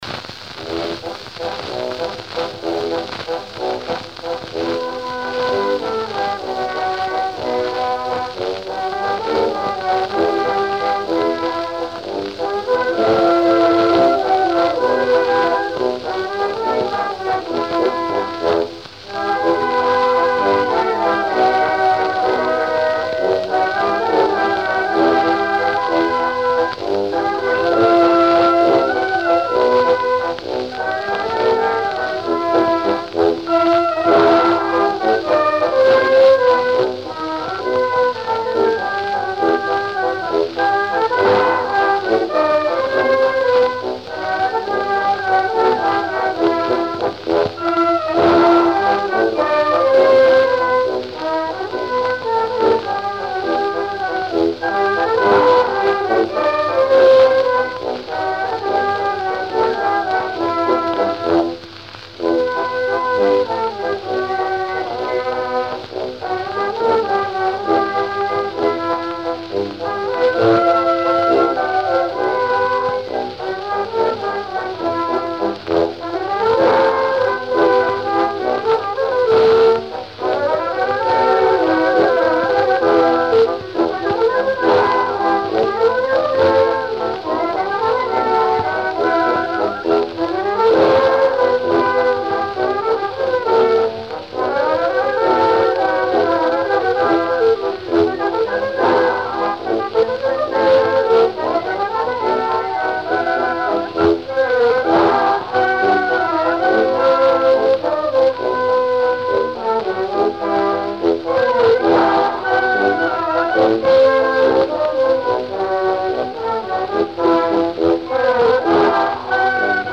Ранняя запись (дореволюционная): "Собств.орк.Экстрафонъ - Дачныя грёзы"
В этом согласен с Вами на !Тоже предпочитаю более ранние(древние) с их шумами очищенным или более поздним исполнениям.